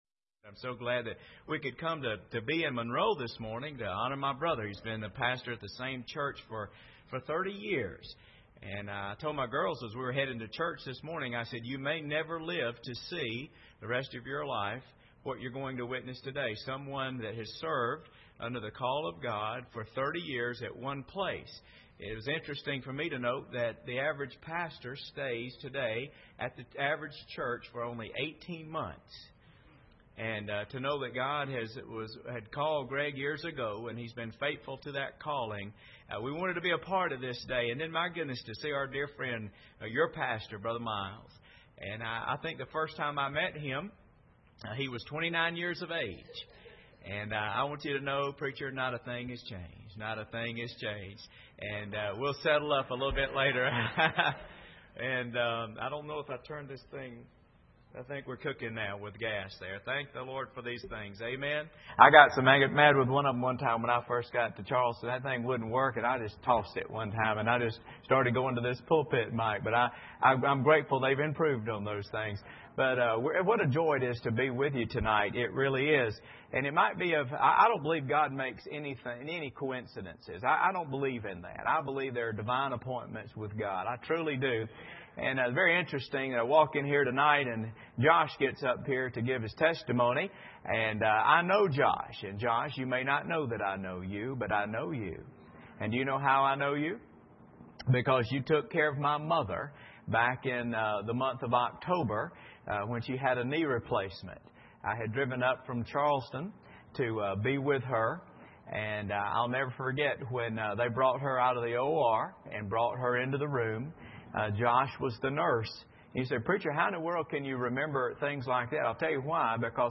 Passage: Hebrews 11:5-6 Service Type: Sunday Evening